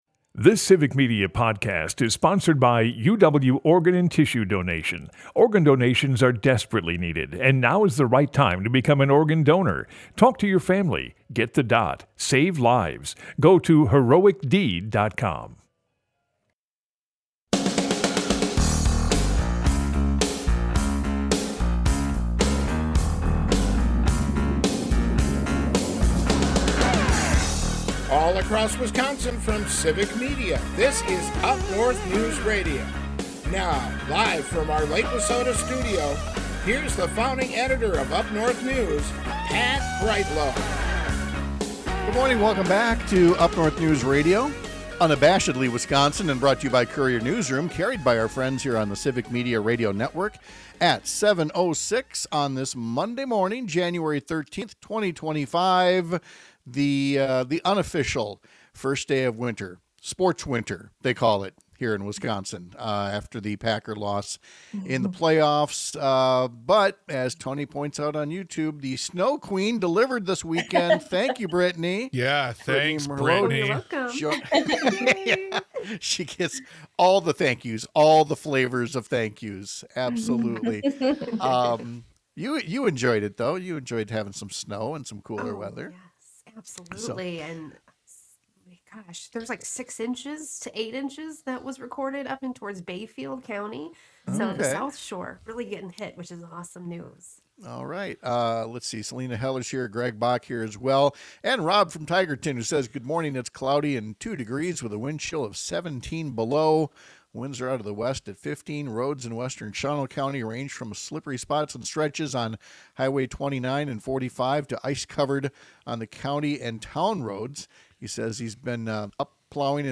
We’ll visit with Wisconsin Supreme Court candidate Susan Crawford, a Dane County judge who is running against Scott Walker’s former attorney general Brad Schimel for a pivotal role on the state’s high court on April 1. And former Gov. Martin Schreiber shares his memories of serving at the same time as former President Jimmy Carter.